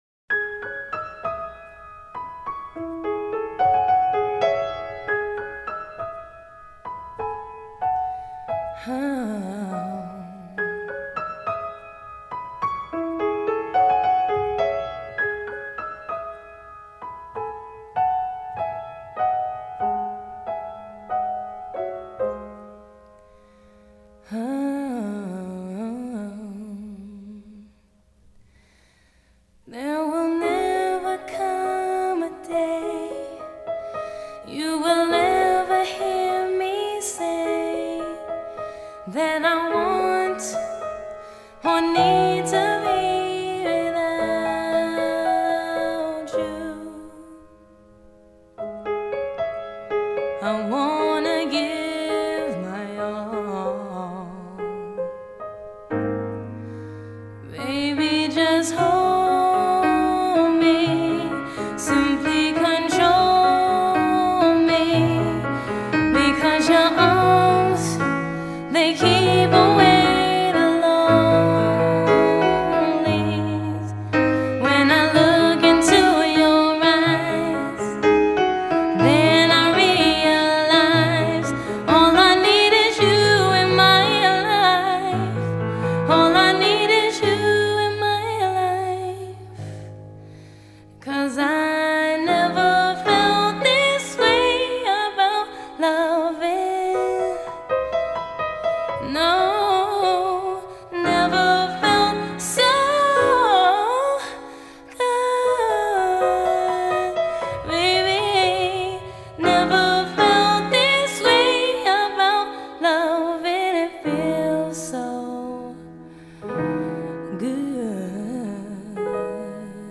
Genre: Soul
Stereo